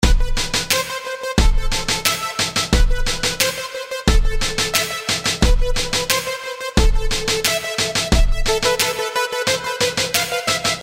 标签： 89 bpm Hip Hop Loops Groove Loops 1.81 MB wav Key : Unknown
声道立体声